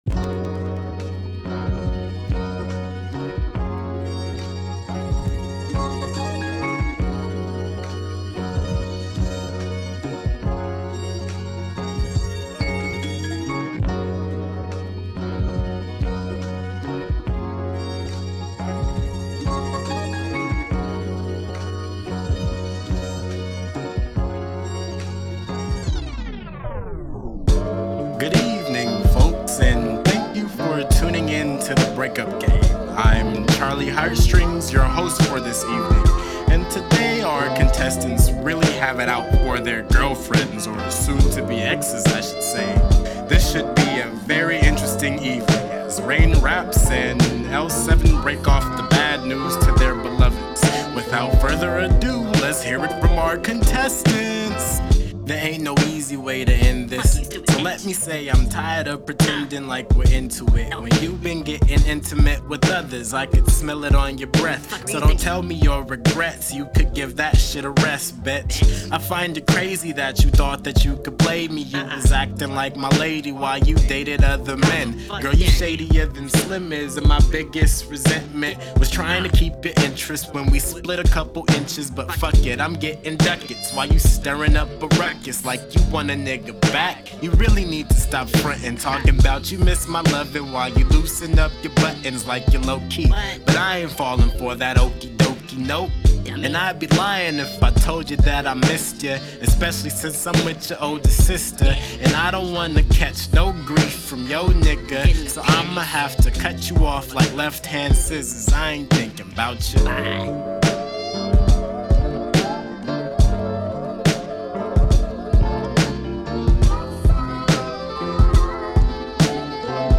Hi all, I have finished a first go [using monitor headphones, as it is super late so my parents are asleep] at this mix for my band's newest song, Maxin'...